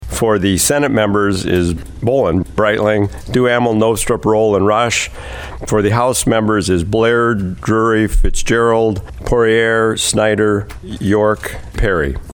President Pro Tempore and E-Board chair Senator Lee Schoenbeck of Watertown gave the names for the Study Committee on Regional Jails and State Correctional Plans.